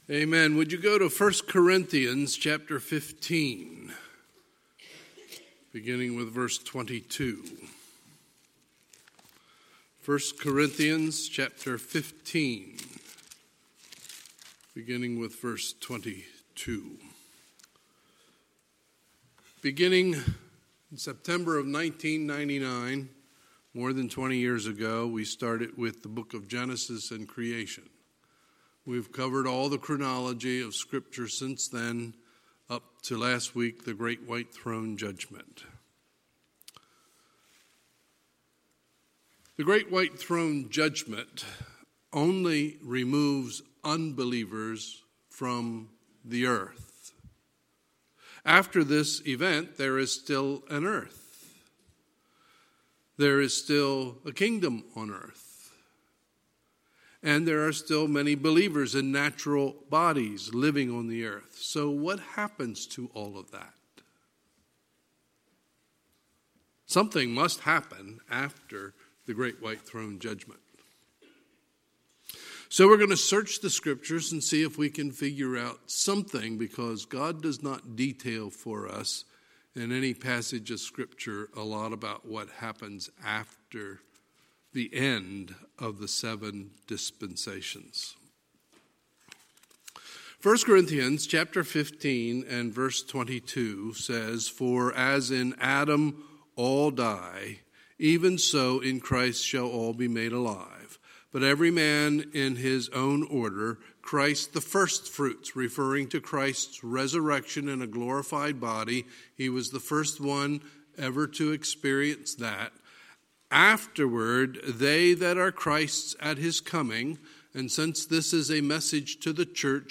Sunday, December 15, 2019 – Sunday Evening Service